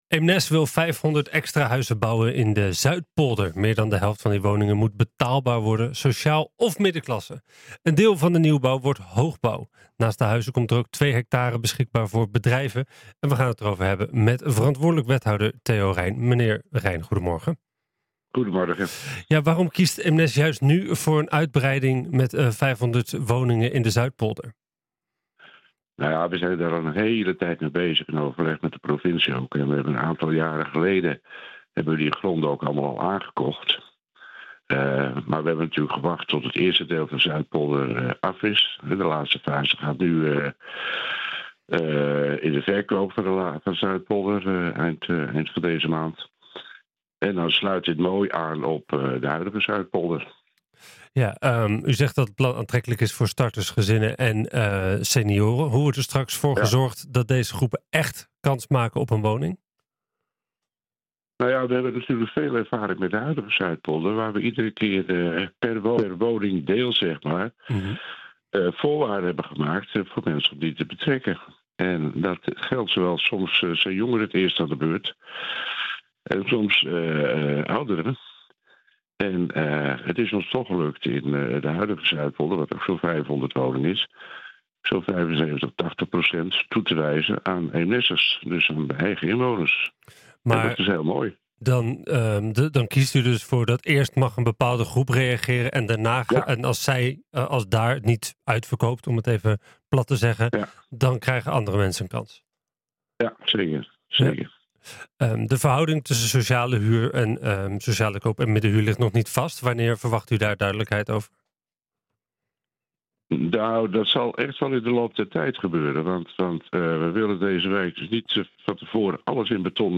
We gaan het erover hebben met verantwoordelijk wethouder Theo Reijn.